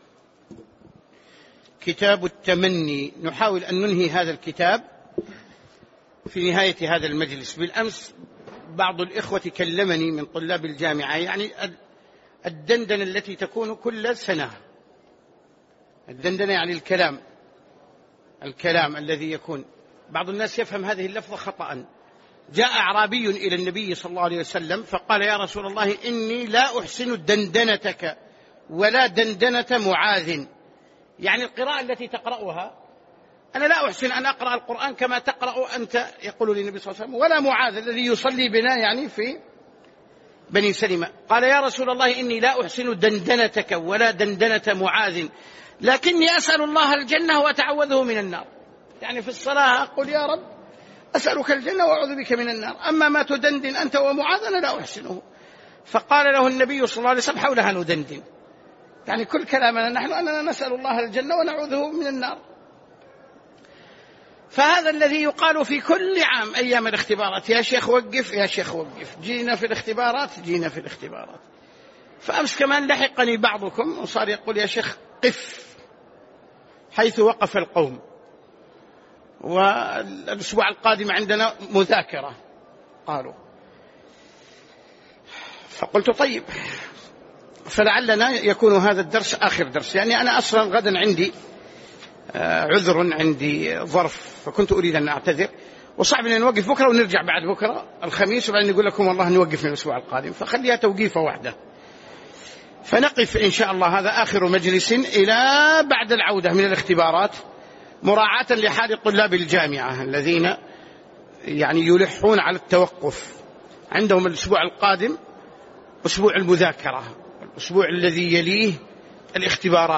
تاريخ النشر ٢٤ ربيع الأول ١٤٣٩ هـ المكان: المسجد النبوي الشيخ